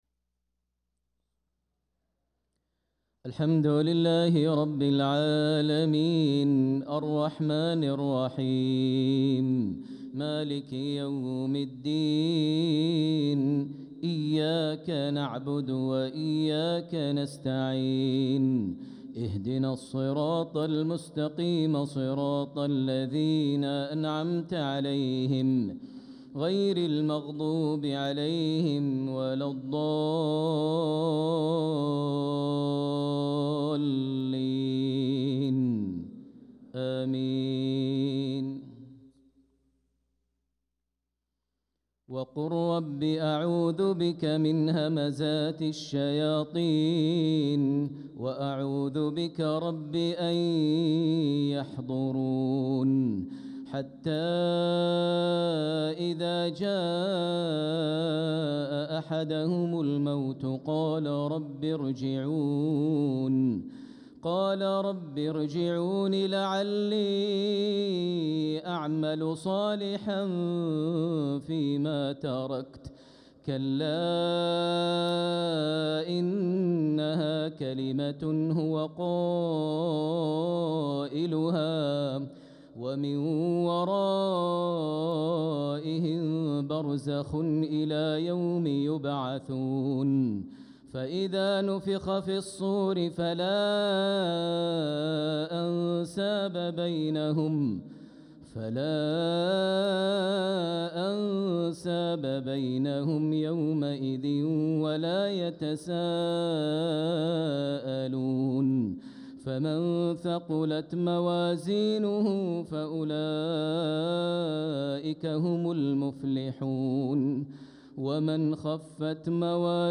صلاة العشاء للقارئ ماهر المعيقلي 22 ربيع الأول 1446 هـ
تِلَاوَات الْحَرَمَيْن .